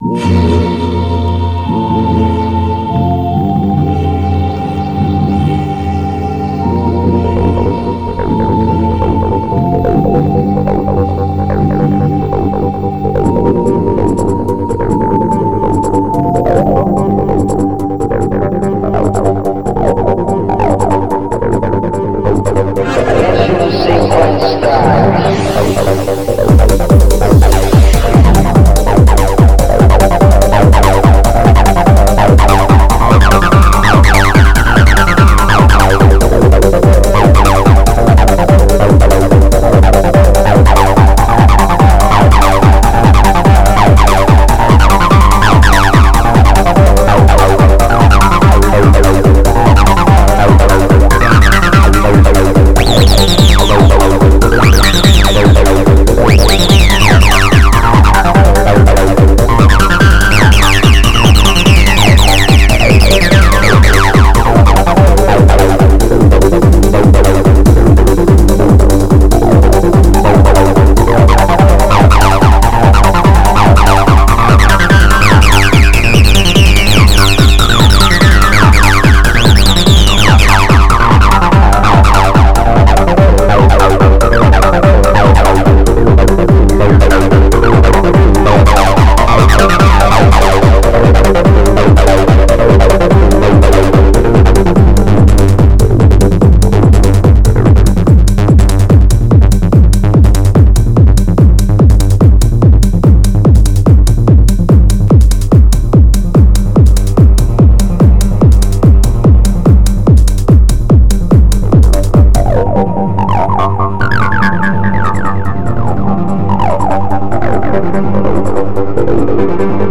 Viel Acid und viel Rhythmus – das war schon prägend!